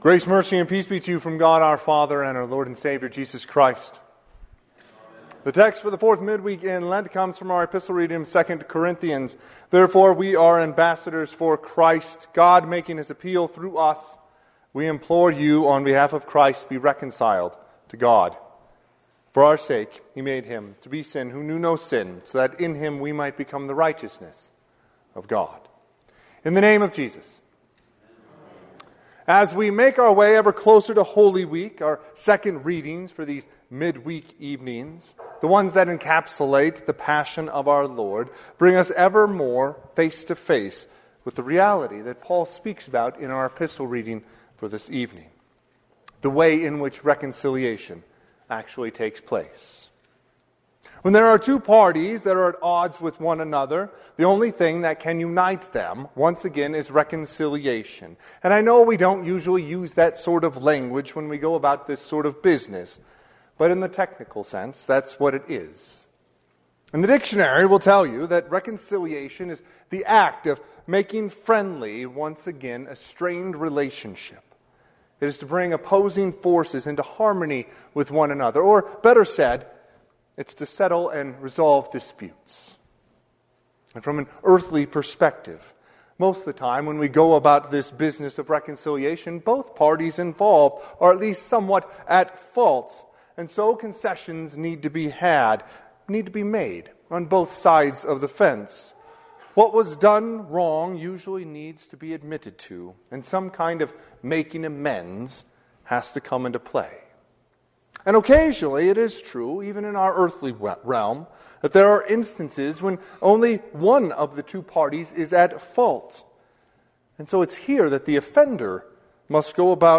Fourth Midweek Service in Lent
Sermon – 4/2/2025